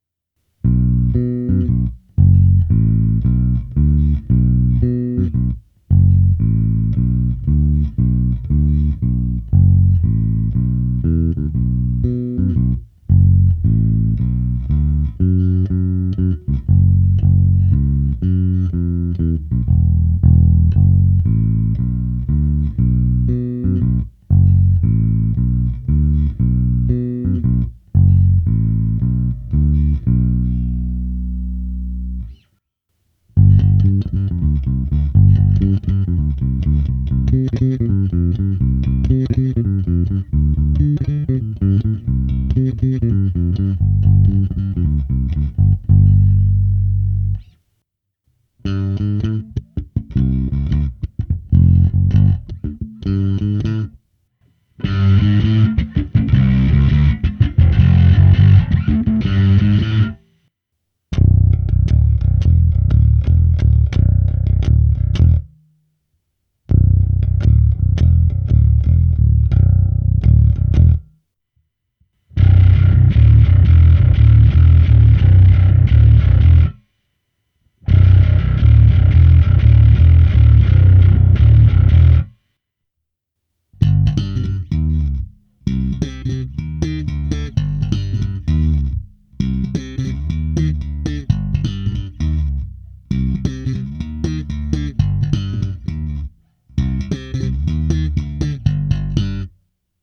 Nahrávka přes Darkglass Alpha Omega Ultra, prsty, se zkreslením a slapem, abych alespoň trochu simuloval, jak hraje basa přes aparát. Díky charakteru použité simulace aparátu jsem basy na baskytaře v tomto případě nechal ve střední poloze a výšky dokonce trochu přidal. V ukázce je i hra na struně H. Hraje fakt slušně.